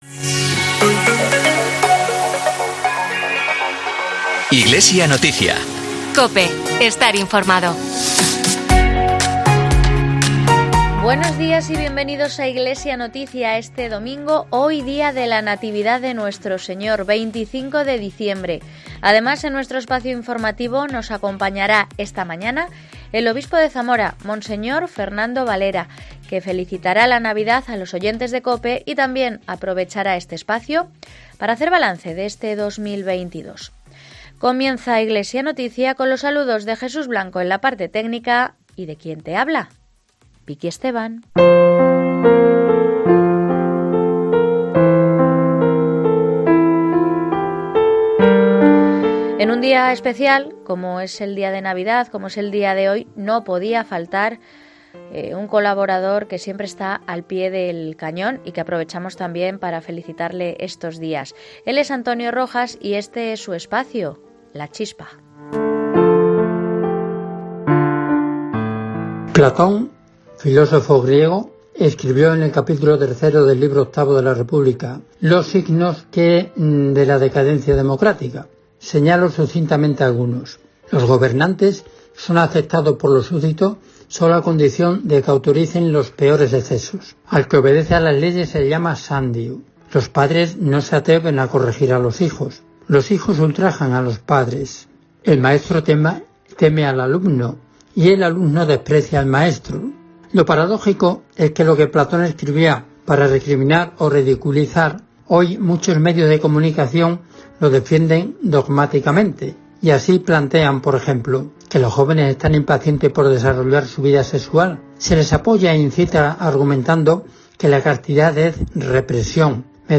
AUDIO: Entrevista al Obispo de Zamora, Fernando Valera